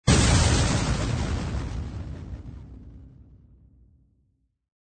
44.1 kHz 震撼大气片头音乐 全站素材均从网上搜集而来，仅限于学习交流。